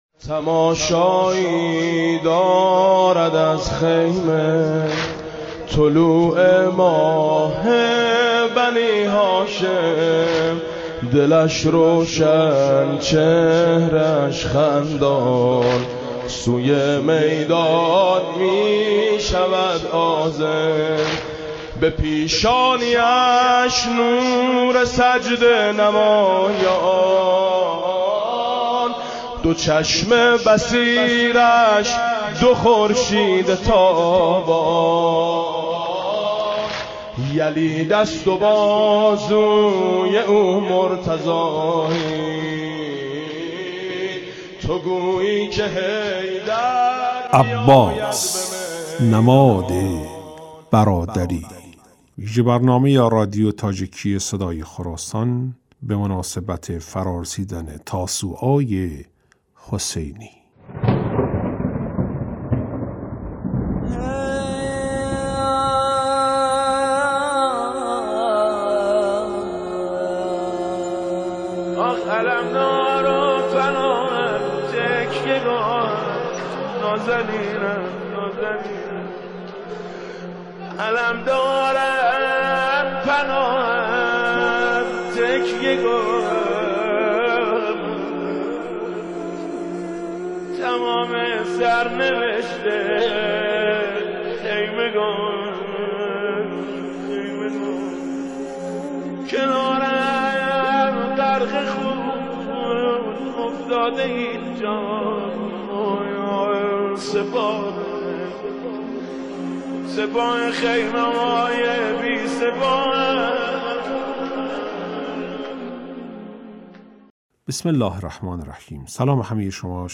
"Аббос намоди бародарӣ" вижа барномае аст, ки ба муносибати айёми Тосуои ҳусайнӣ дар радиои тоҷикии Садои Хуросон таҳия ва пахш шудааст.